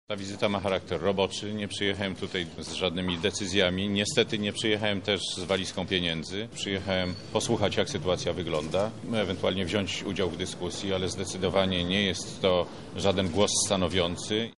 – mówi Konstatny Radziwiłł, minister zdrowia.